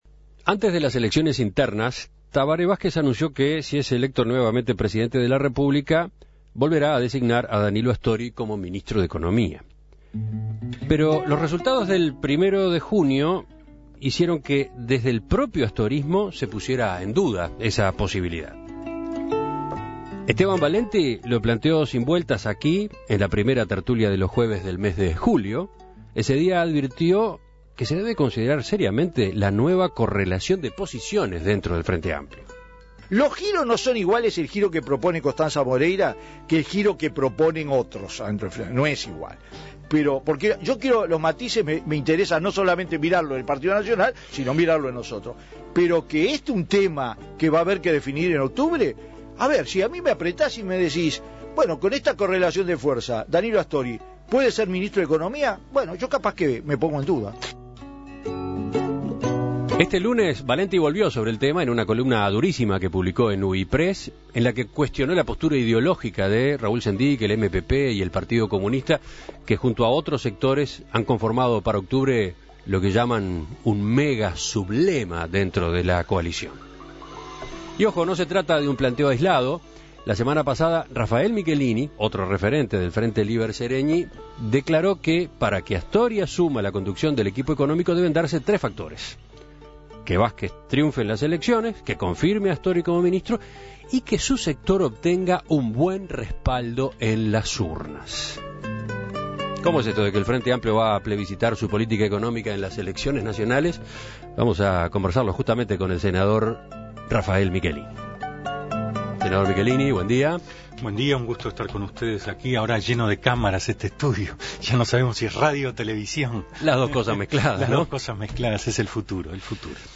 Por este motivo, En Perspectiva entrevistó al senador frenteamplista Rafael Michelini. El legislador resaltó la necesidad de respaldar la política económica impulsada en los últimos 10 años.